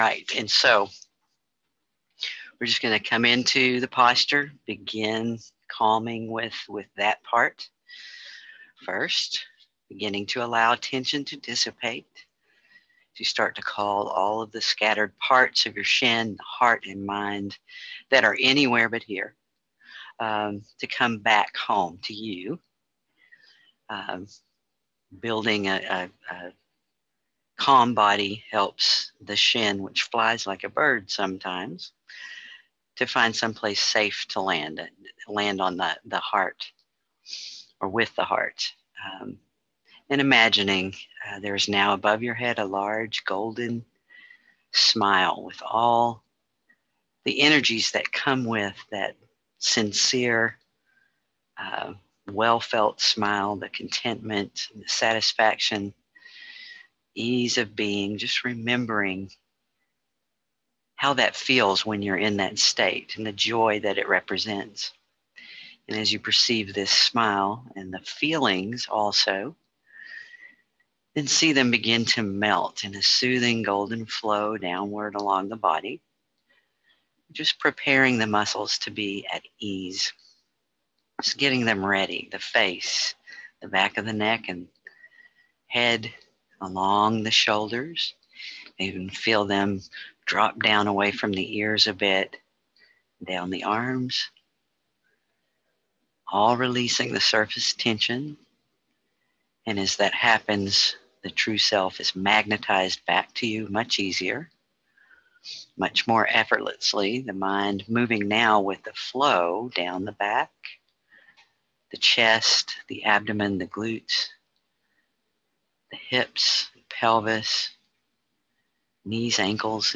recording of guided meditation click for "Standing and Breathing)